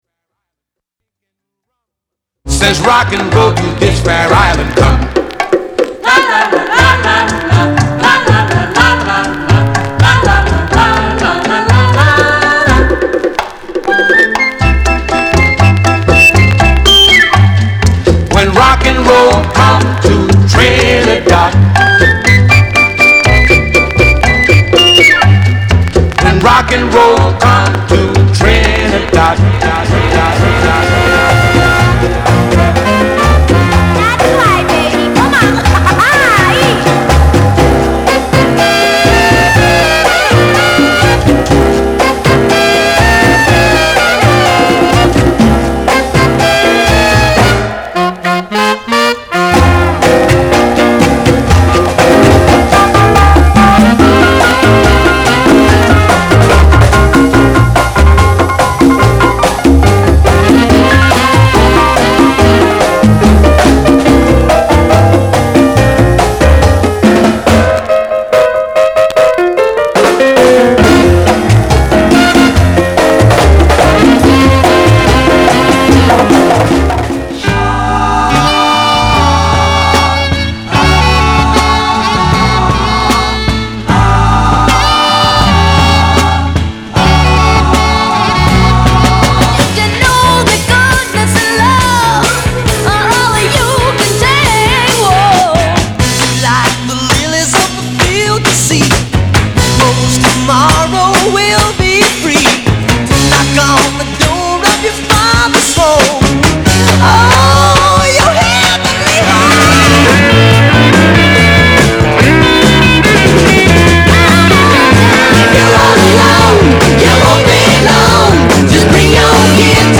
category Vocal
Path Yahoo Bid > Music > Vinyl Records > Jazz > Vocal